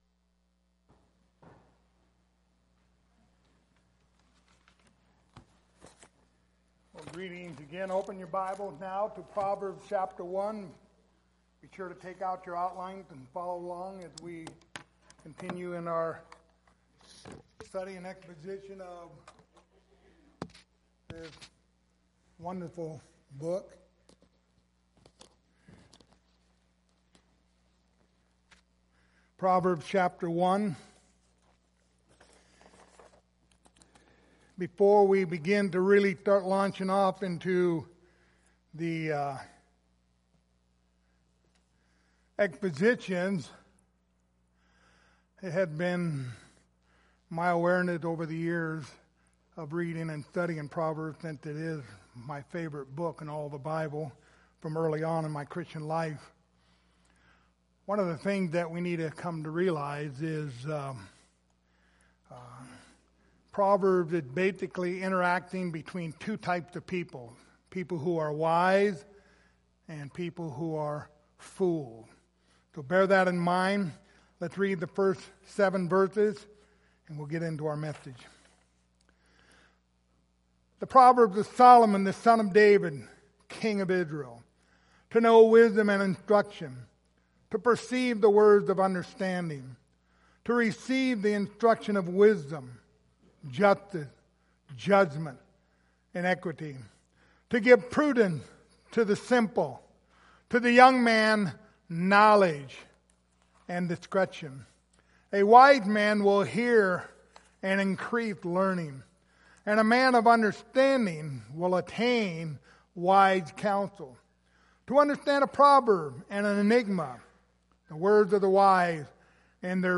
Passage: Proverbs 1:7 Service Type: Sunday Morning Topics